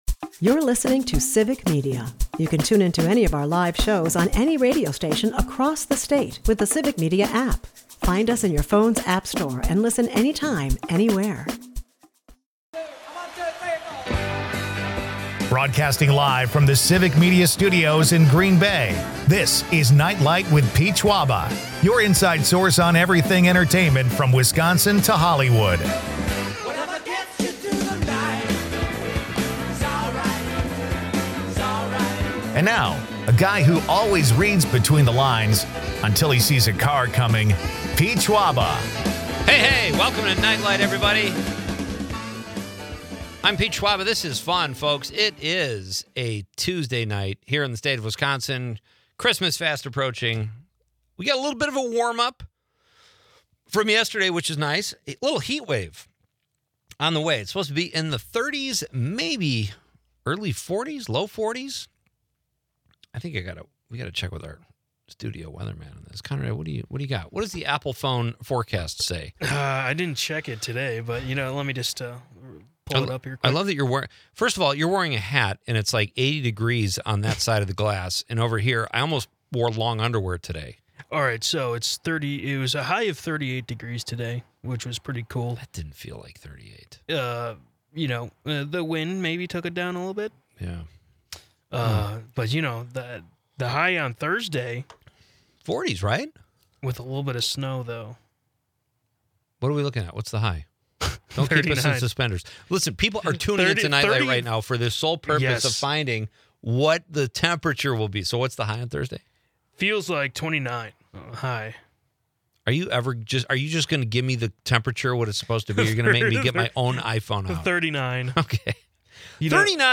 Rock ‘n’ Roll for a Cause: Freezing Man Festival (Hour 1)